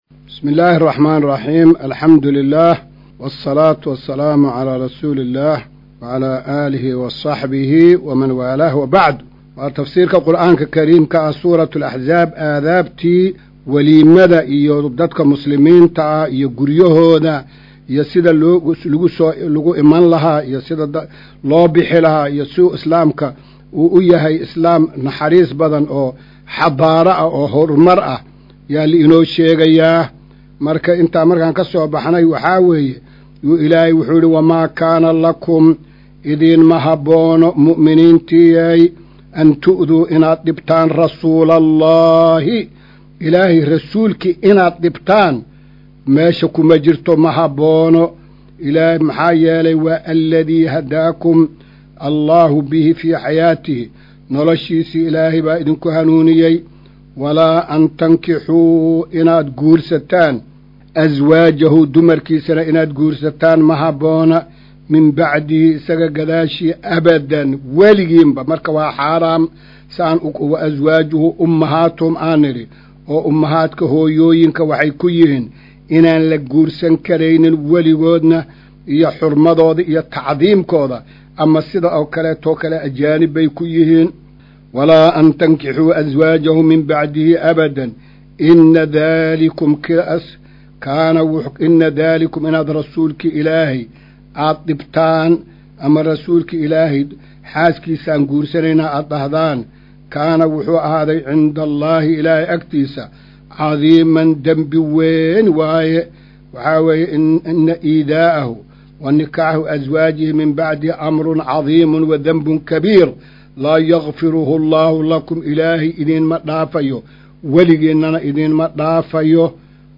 Maqal:- Casharka Tafsiirka Qur’aanka Idaacadda Himilo “Darsiga 201aad”